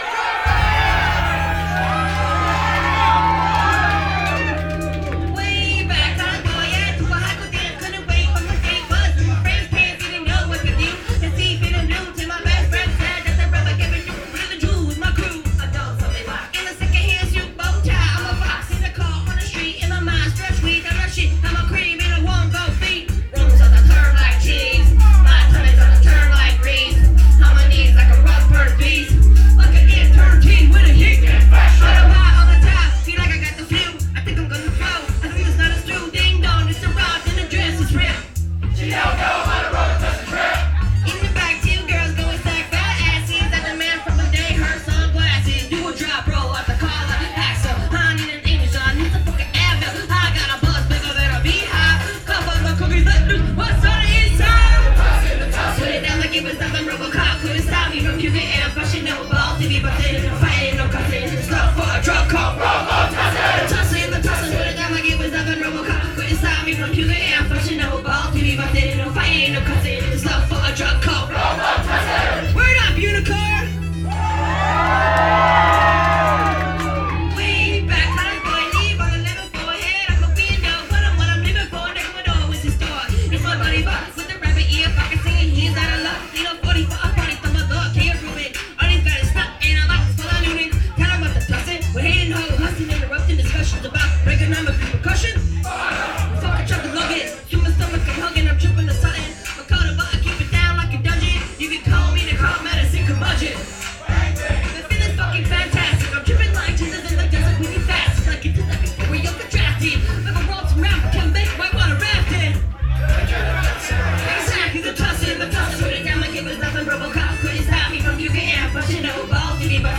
atlanta, ga at the drunken unicorn on september 3rd 2005
audience microphone recording